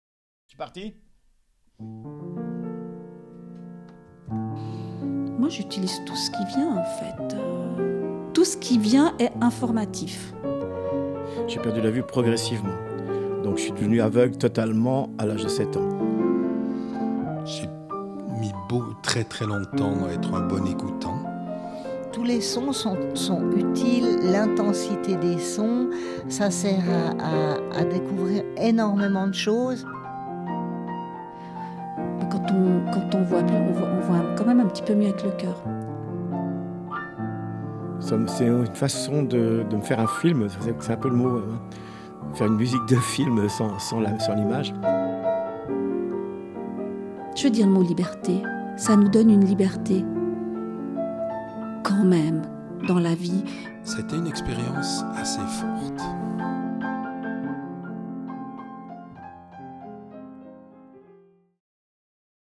Documentaire radiophonique